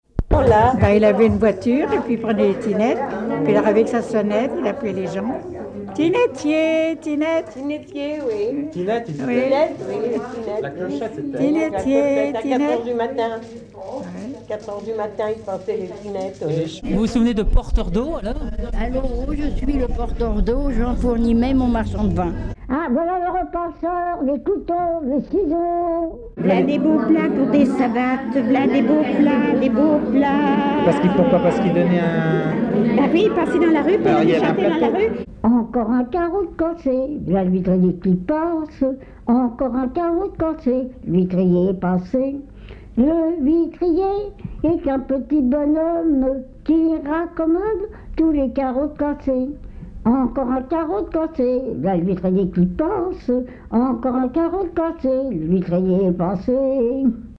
Suite de cris de rue - Service
Pièce musicale inédite